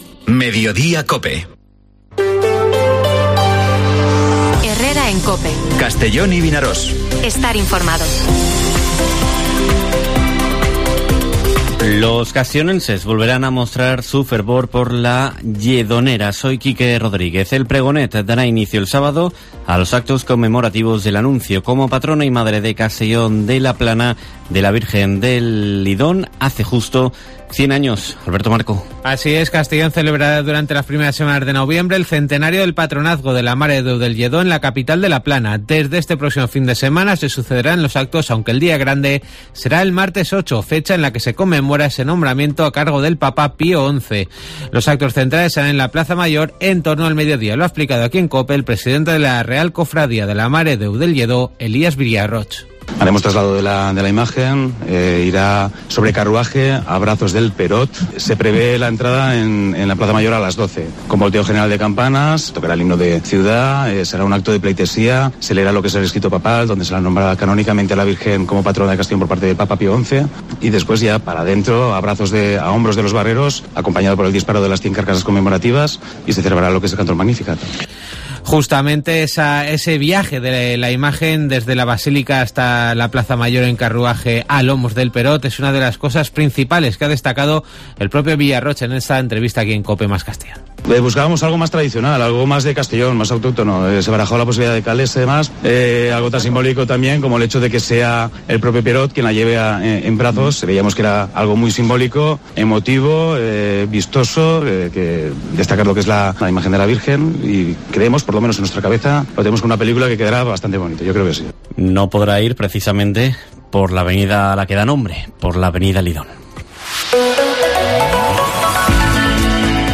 Informativo Mediodía COPE en la provincia de Castellón (31/10/2022)